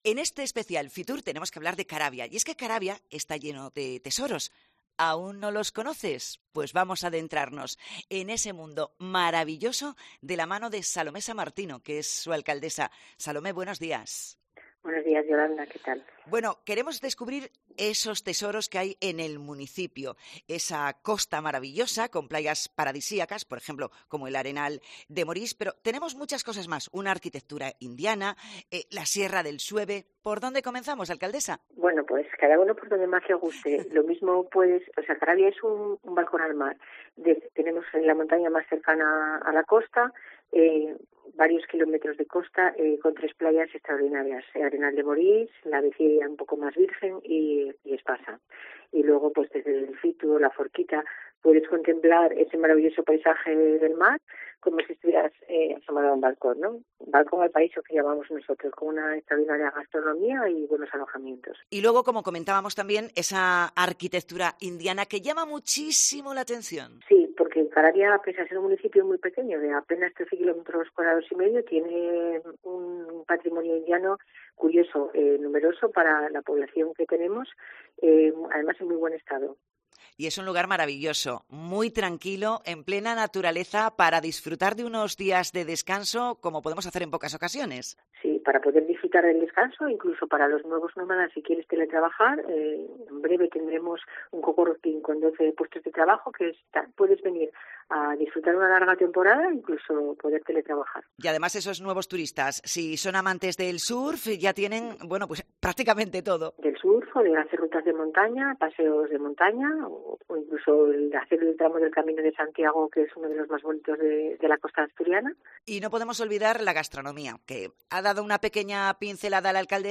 En el especial de COPE Asturias desde FITUR 2024, hablamos con la alcaldesa del concejo, Salomé Samartino: "Puedes disrutar del Cantábrico con unas vistas impresionantes"
FITUR 2024: Entrevista a Salomé Samartino, alcaldesa de Caravia